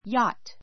jɑ́t
（ ⦣ ch は発音しない）